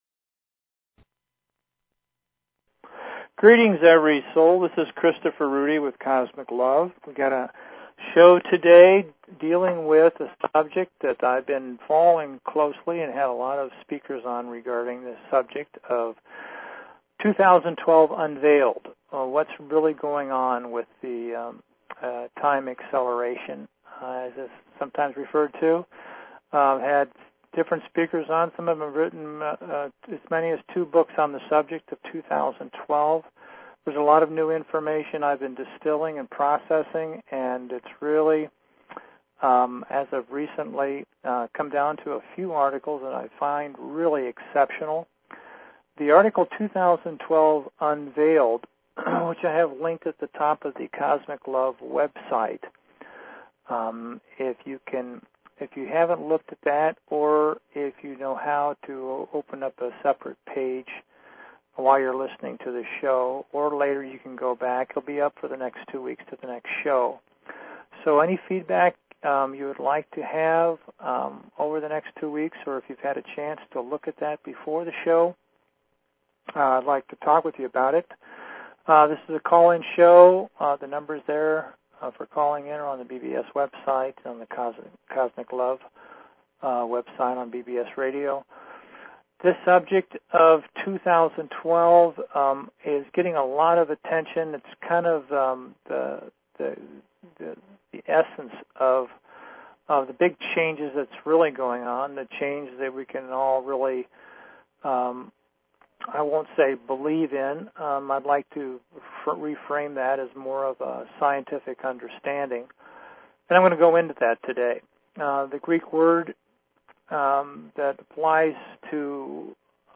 Talk Show Episode, Audio Podcast, Cosmic_LOVE and Courtesy of BBS Radio on , show guests , about , categorized as